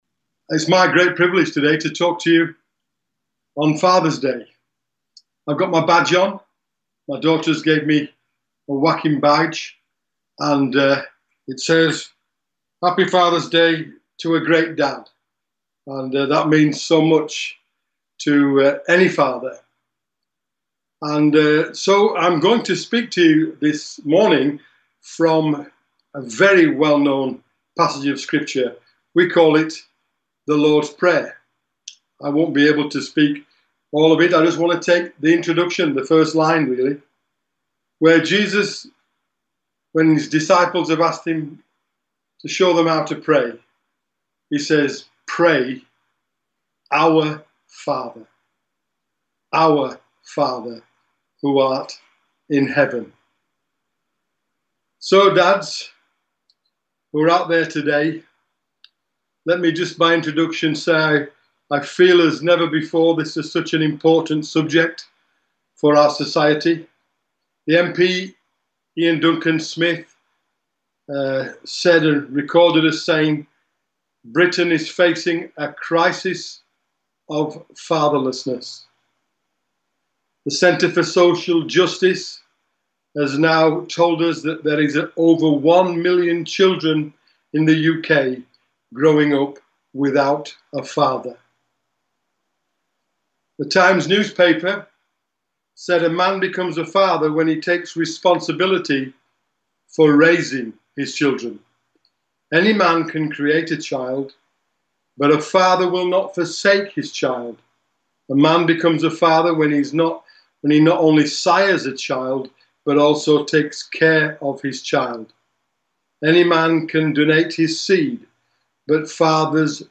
Father’s day message.